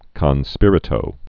(kŏn spîrĭ-tō, kōn)